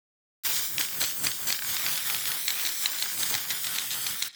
spray_loop.wav